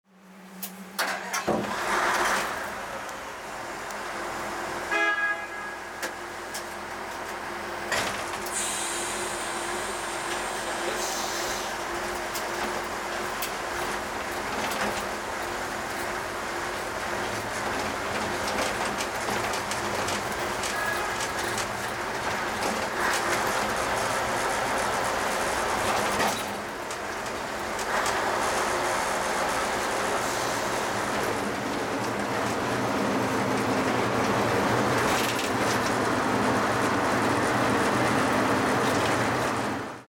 機械のモーター音やアナウンス、発車の合図......。地下鉄、都電荒川線、都営バスのそれぞれの場所でしか聞くことができない音を収録しました。
第13回地下鉄大江戸線「保線作業車の走行音」